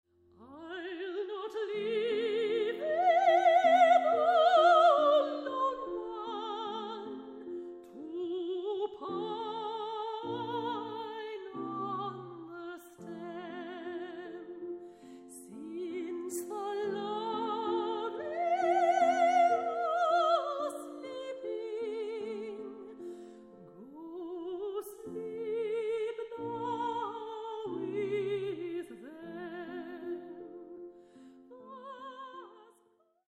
ravishing Australian soprano
Soprano
Piano